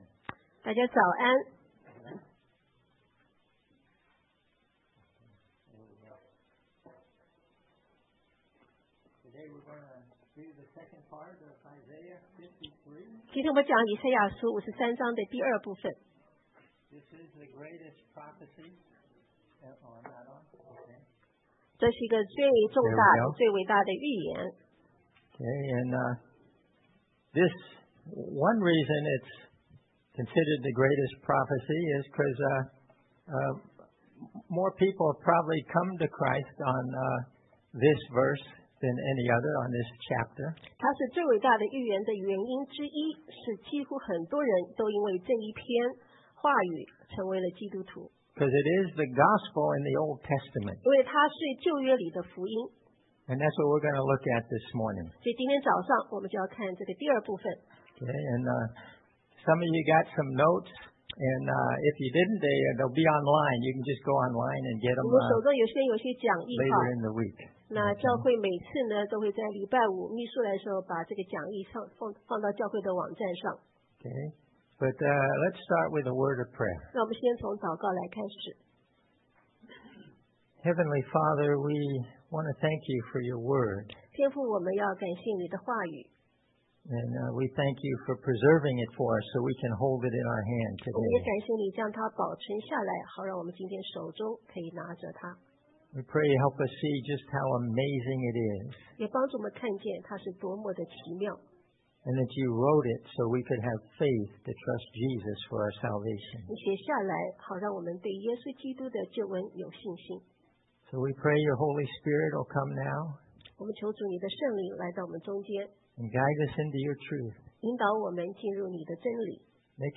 Isaiah 53 Service Type: Sunday AM Bible Text